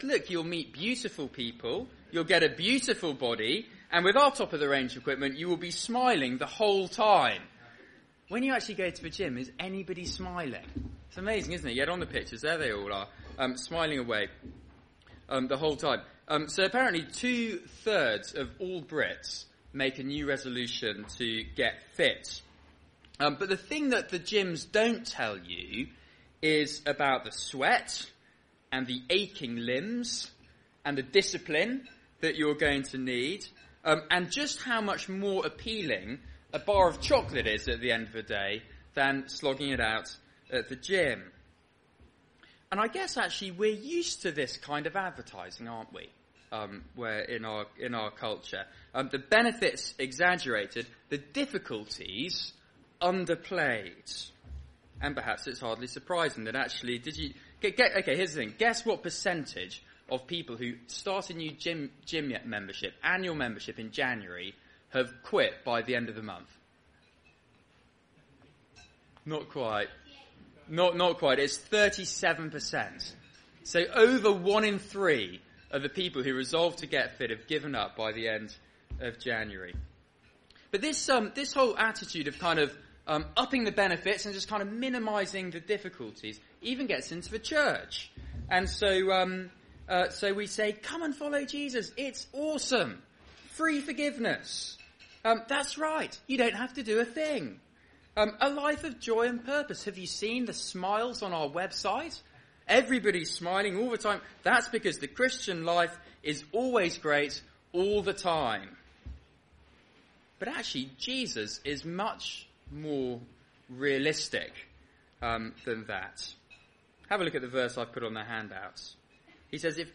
Media for Seminar on Thu 20th Nov 2014 19:30 Speaker